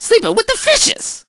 crow_kills_02.ogg